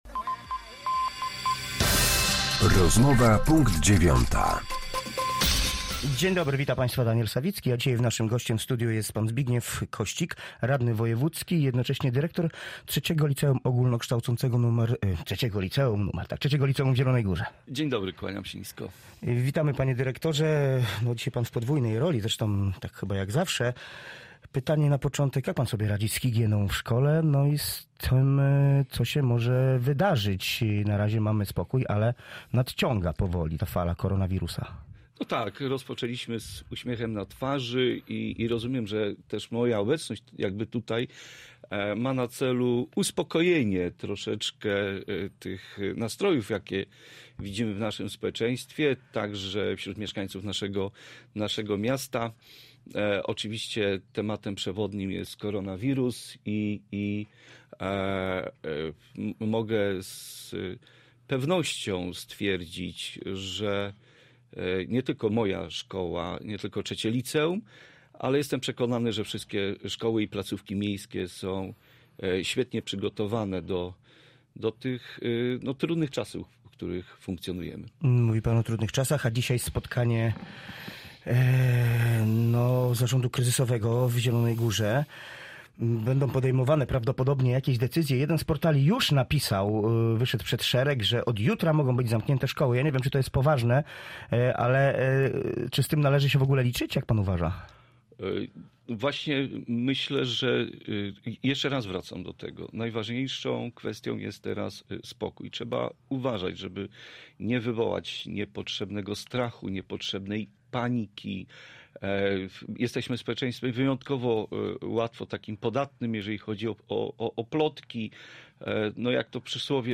Z radnym wojewódzkim klubu PiS rozmawia